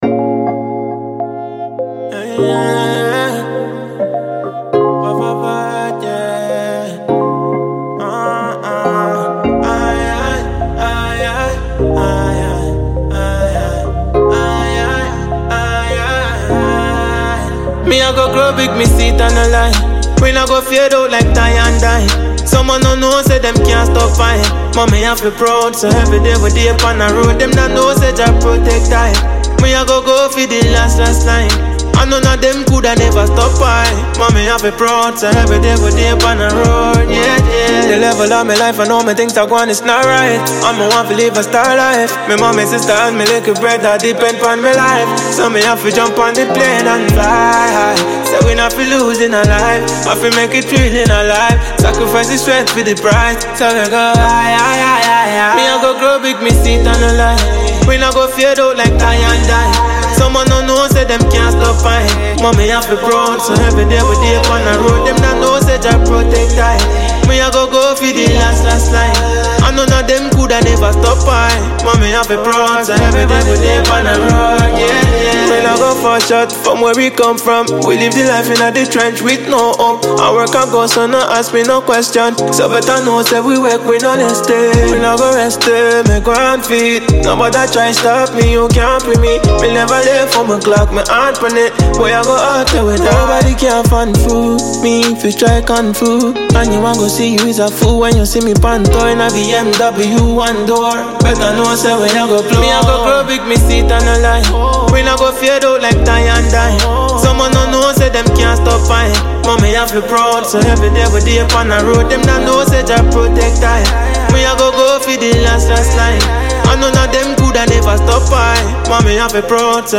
Ghanaian dancehall and reggae
and signature calm-but-commanding delivery.
The hook is catchy yet meaningful